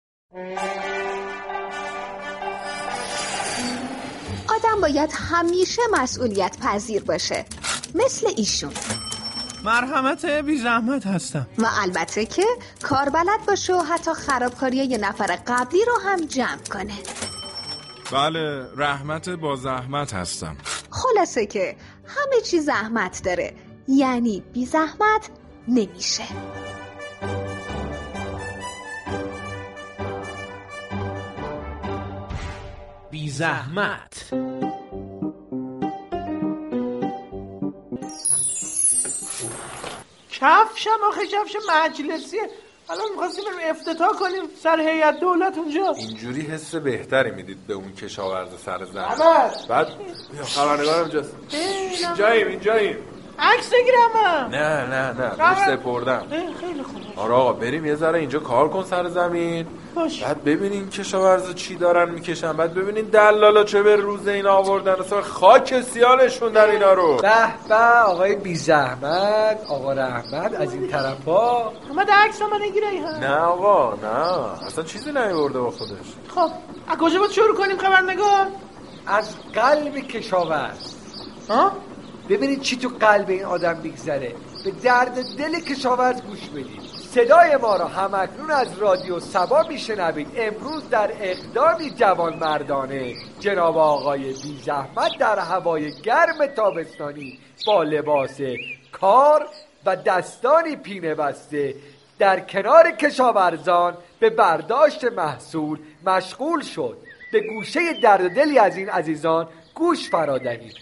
این برنامه در قالب نمایش رادیویی با حضور شخصیت های «خبرنگار، مسئول و راننده» هر روز یك نمایش به بازتاب دغدغه های مردم می پردازند.